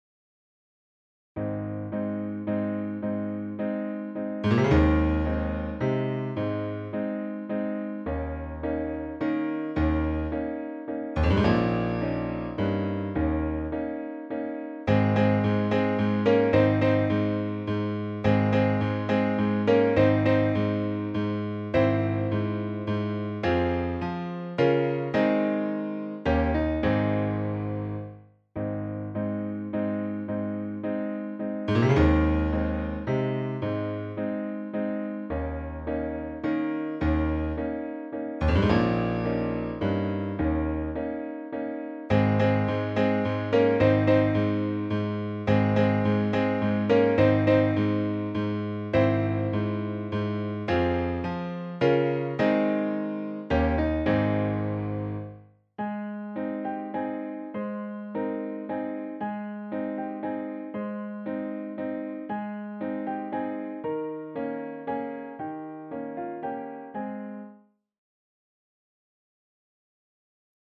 pour piano